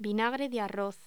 Locución: Vinagre de arroz
voz